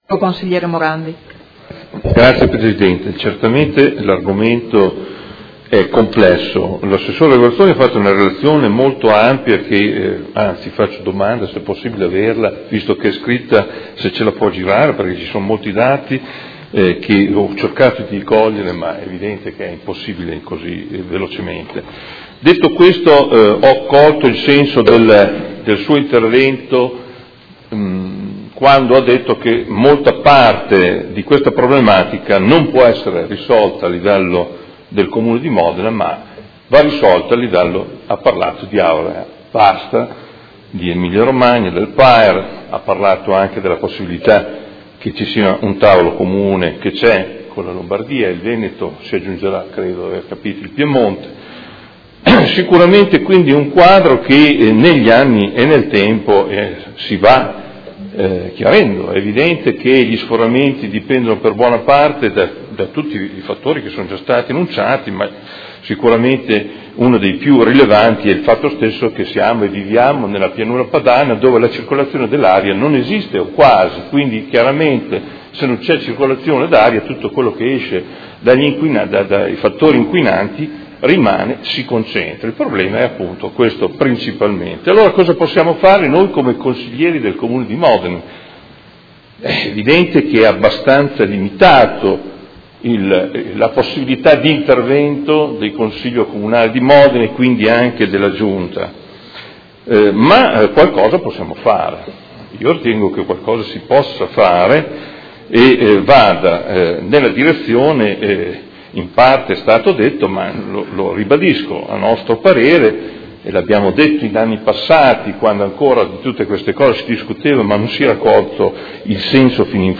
Seduta del 02/03/2017 Dibattito. Interrogazione del Gruppo Per Me Modena avente per oggetto: La qualità dell’aria.